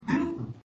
Jump_01
Category 🎮 Gaming
arcade game Jump Mario rpg-game sound effect free sound royalty free Gaming